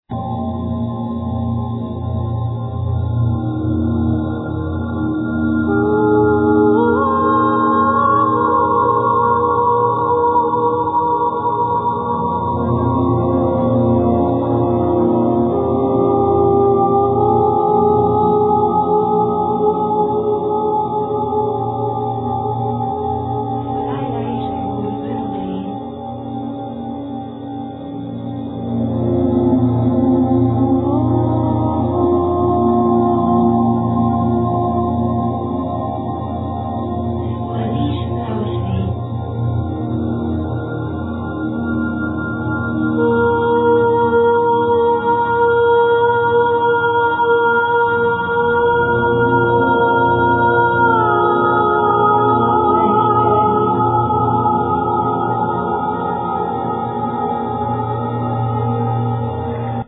Vocals,Rainstick, Mandlin, Bells, Windchime, Ocarina, Shells
Narnian horn, Cymbals, Paper drum, Keyboards